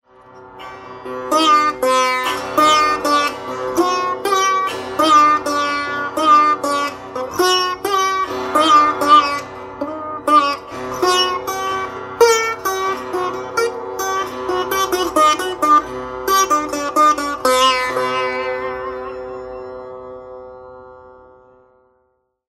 SITAR
Il sitar ha una corda sulla quale suonare le melodie (accordata in F) e altre 6 corde sopra di essa per l'accompagnamento, accordate in C e G. Inoltre, sotto queste corde, sono presenti altre 11 corde risonanti, da accordare secondo la scala in uso nella particolare canzone che si sta eseguendo.
Qui potete sentire alcuni miei brevi fraseggi su questo strumento:
SitarDemo1.mp3